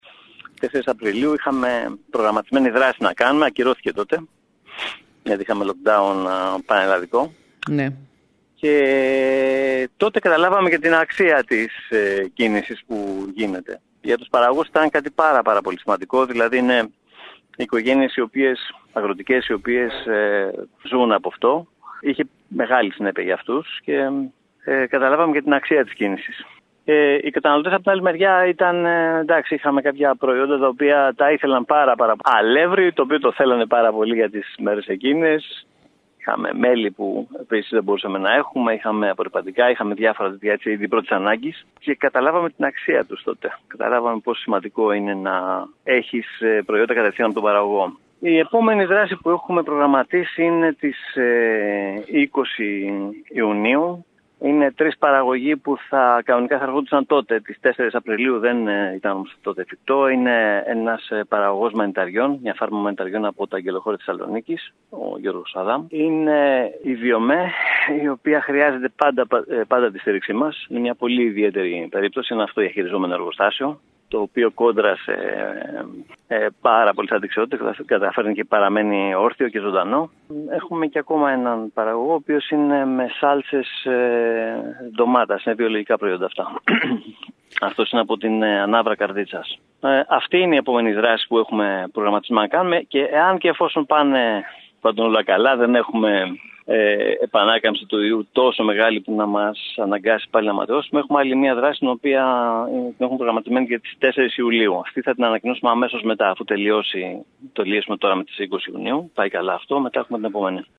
μιλώντας σήμερα στο σταθμό μας